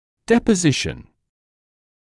[ˌdepə’zɪʃn][ˌдэпэ’зишн]депонирование, отложение; осадок